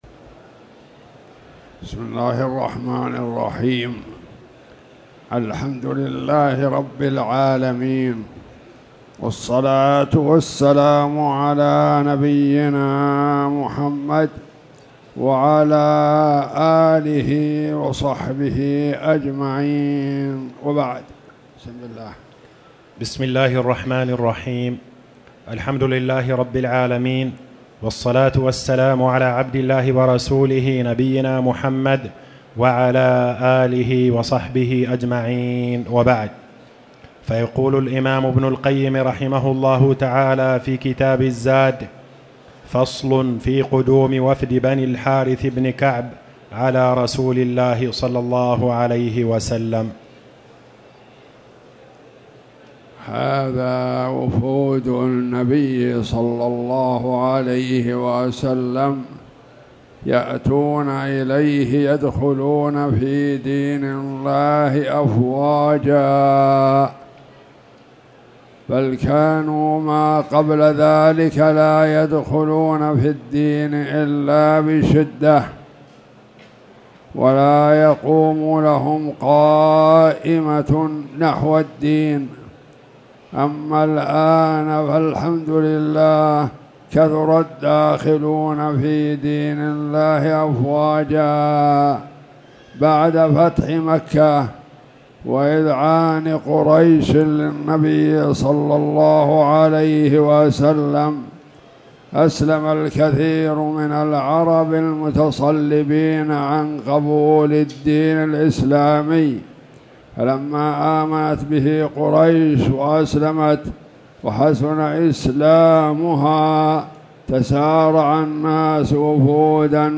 تاريخ النشر ٢٥ جمادى الآخرة ١٤٣٨ هـ المكان: المسجد الحرام الشيخ